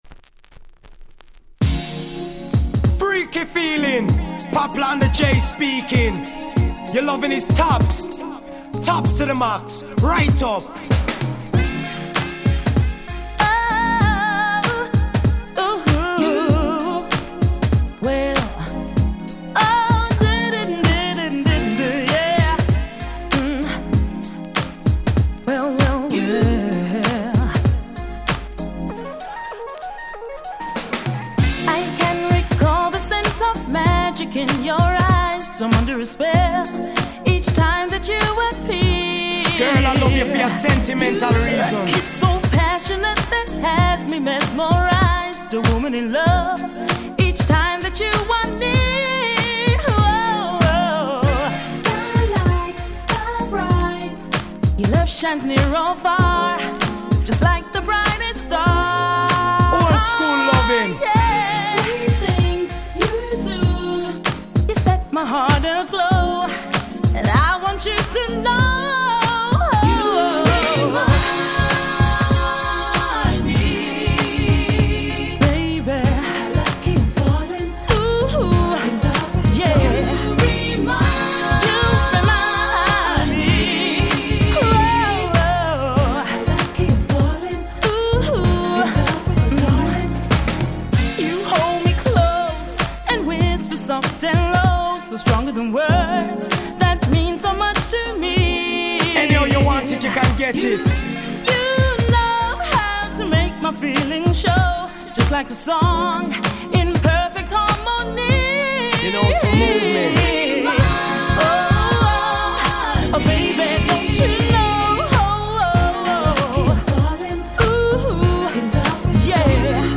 99' Nice UK R&B !!